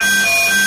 AFX_SPACEALARM_2_DFMG.WAV
Space Alarm 2